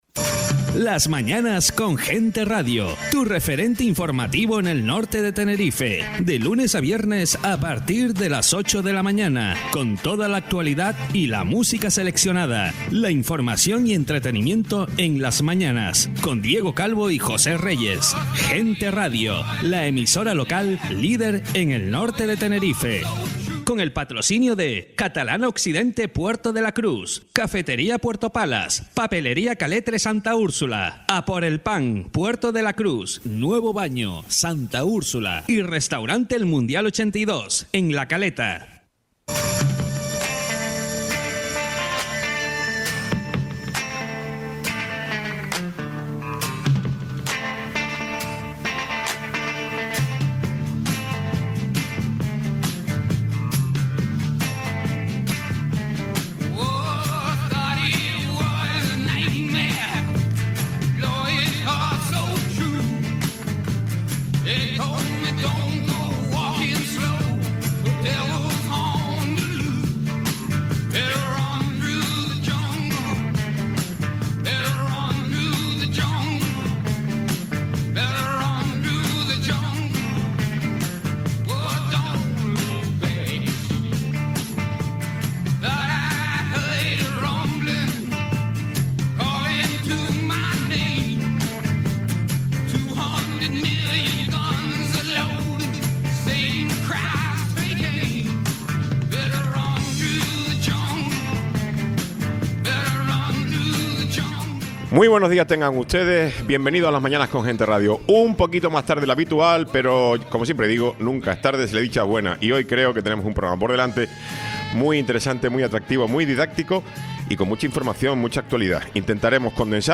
Tiempo de entrevista con Rosa Dávila, dipudata autonómica de CC